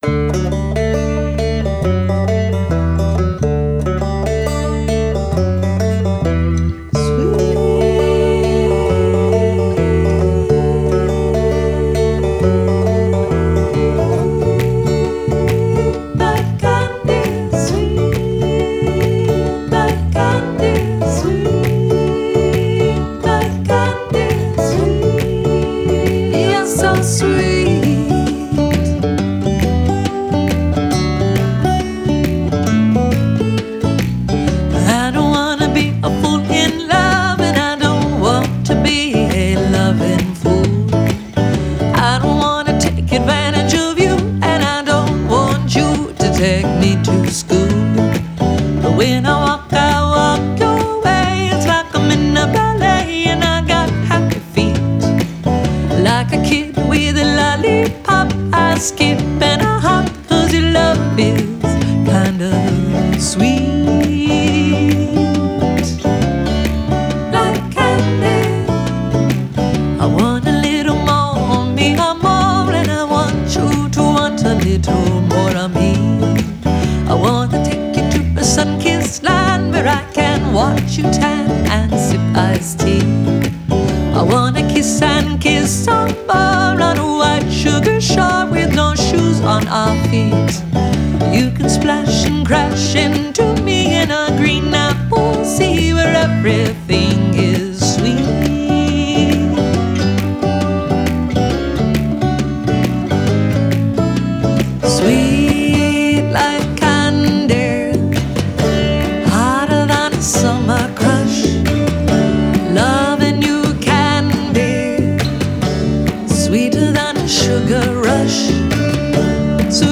Genre: Alternative Folk, Singer/Songwriter, Country